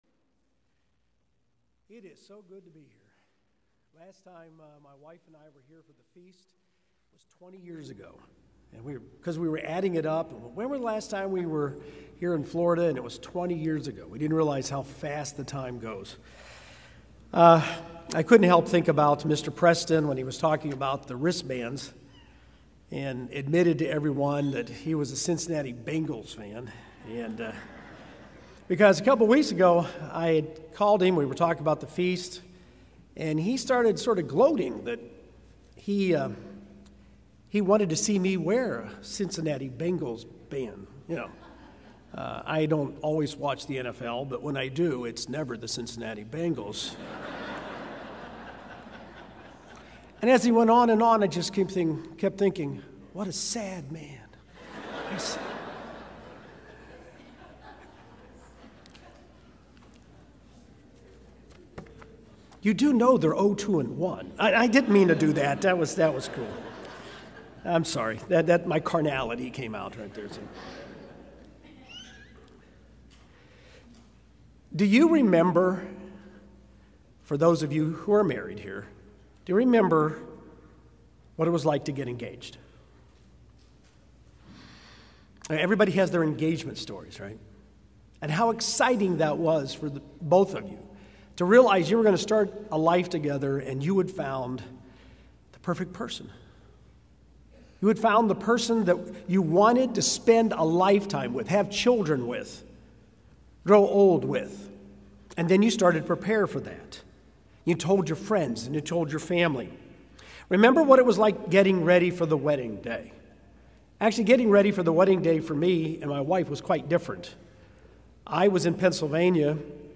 This sermon was given at the Panama City Beach, Florida 2020 Feast site.